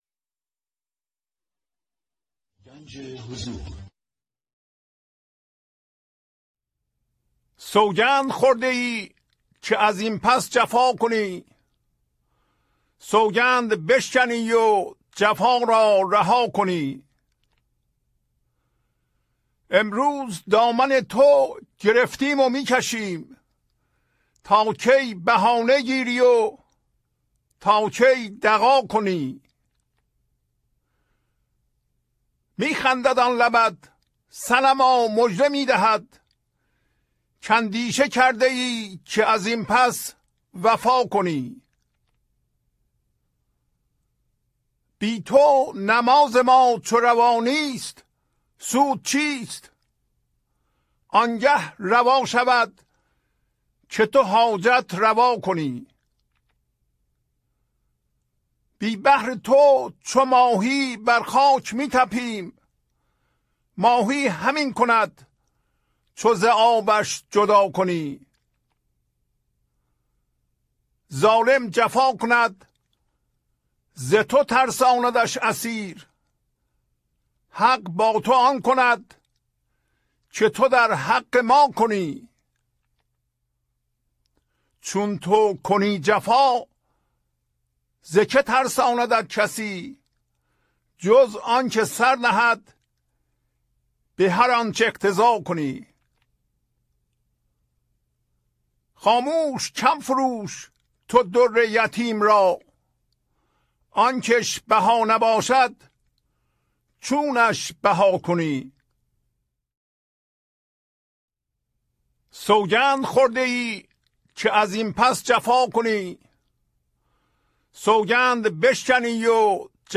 خوانش تمام ابیات این برنامه - فایل صوتی
1029-Poems-Voice.mp3